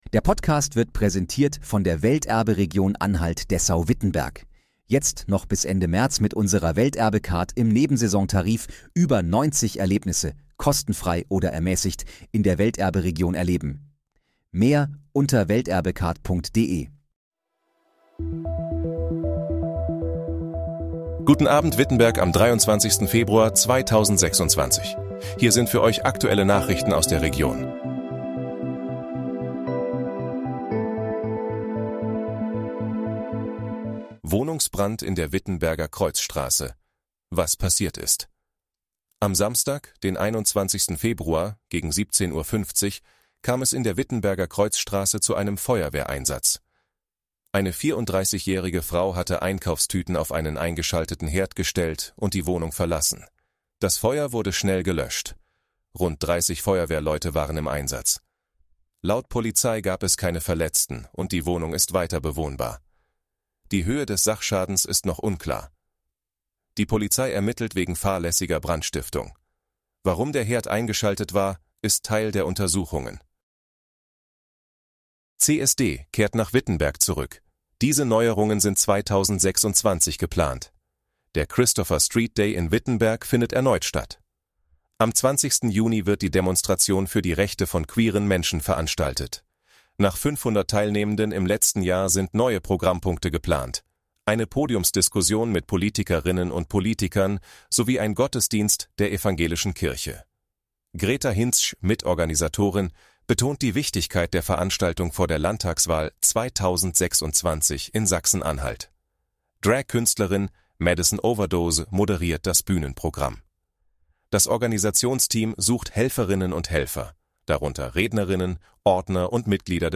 Guten Abend, Wittenberg: Aktuelle Nachrichten vom 23.02.2026, erstellt mit KI-Unterstützung
Nachrichten